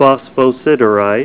Say PHOSPHOSIDERITE Help on Synonym: Synonym: ICSD 34007   Metastrengite   PDF 33-666